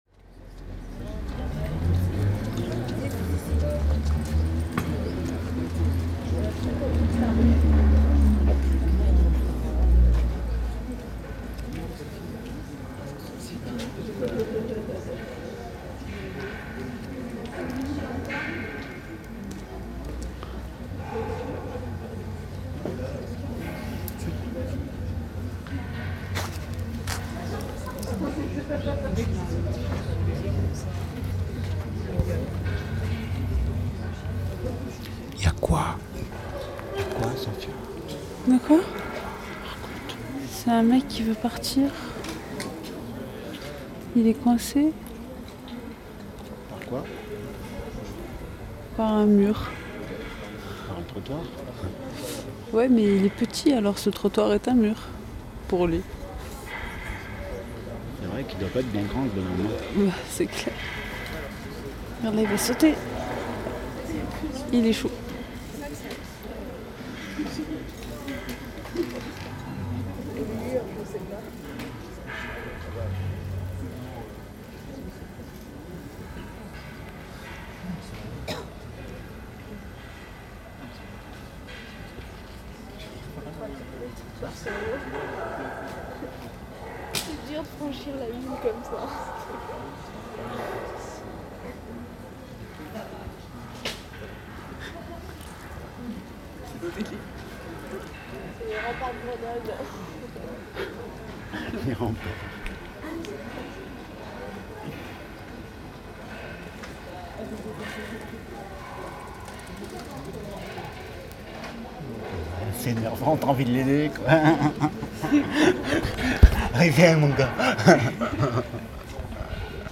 Rue Cuvier - Grenoble, un soir par an on éteint l’éclairage urbain & ce sont les habitants du quartier qui s’organisent pour créer eux-mêmes des ambiances lumineuses.
Reportage audio